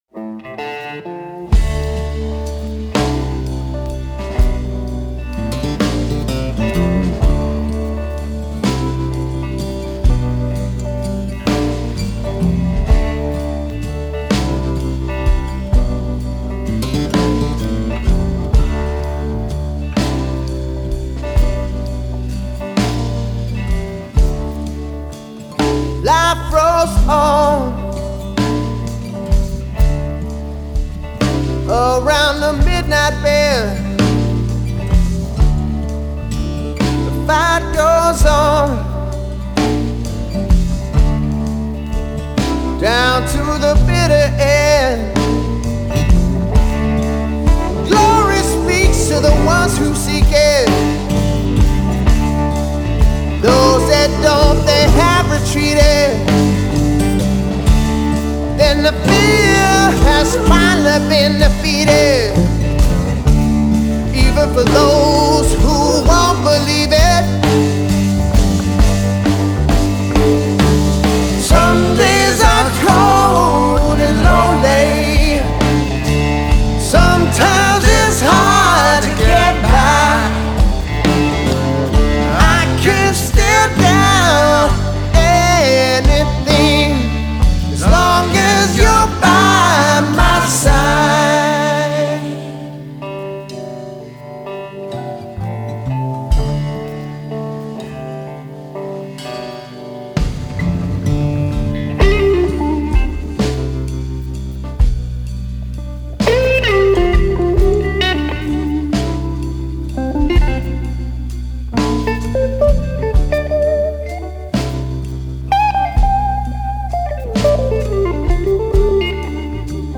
Genre: Blues, Blues Rock